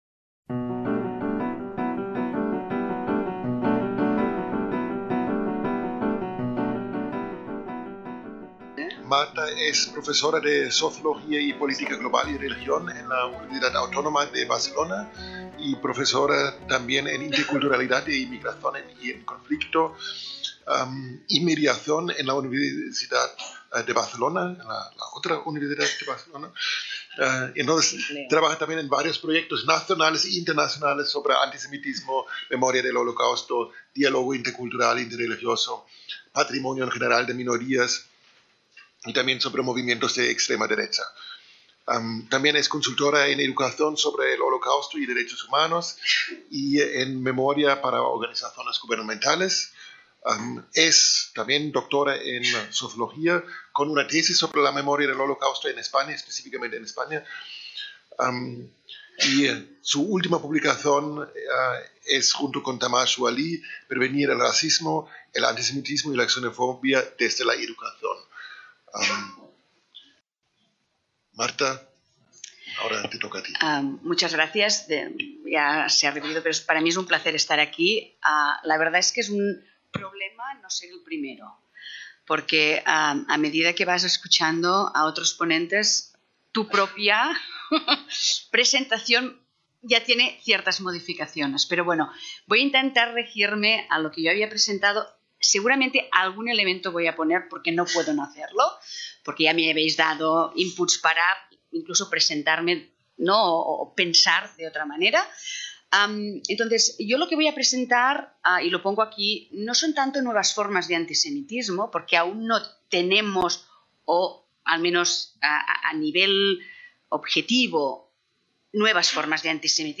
VII SEMINARIO INTERNACIONAL CONTRA EL ANTISEMITISMO